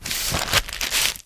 MISC Soft Plastic, Scrape 03.wav